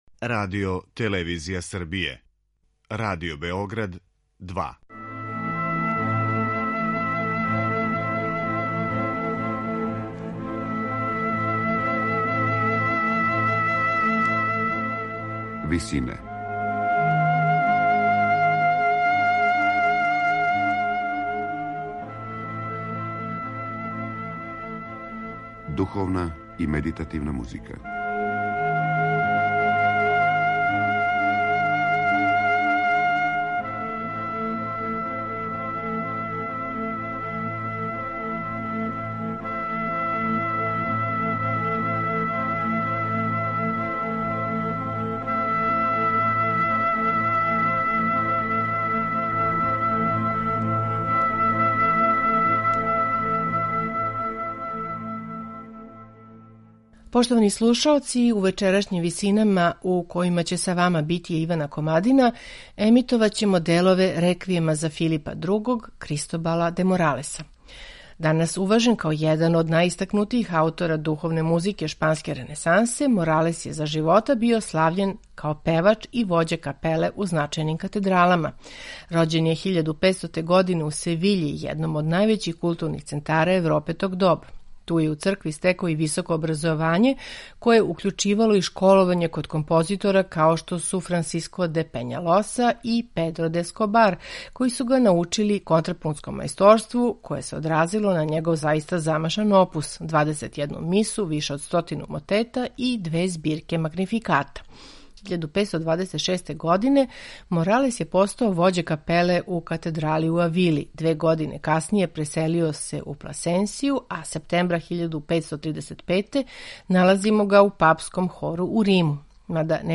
медитативне и духовне композиције
У вечерашњим Висинама Моралесов Реквијем за Филипа Другог слушамо у извођењу ансамбла Gabrieli Consort , под управом Пола Макриша.